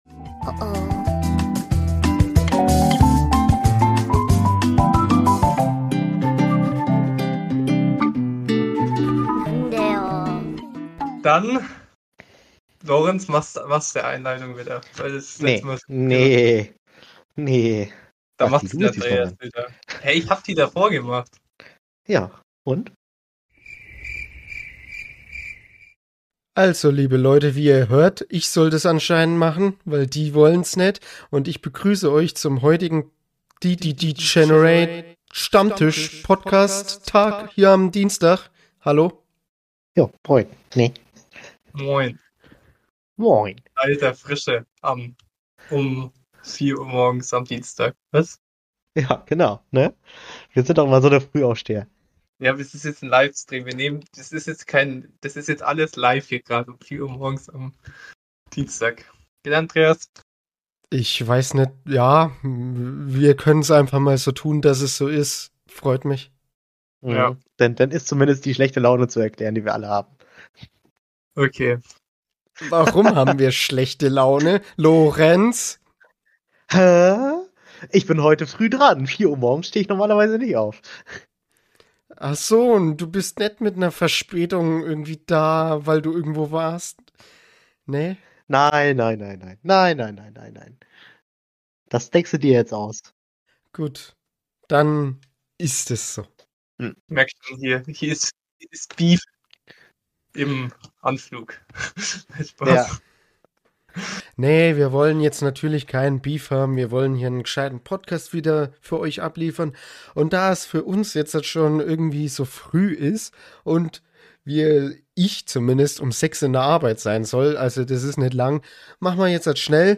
In dieser Episode starten wir mit einem Knaller: Wir sind live!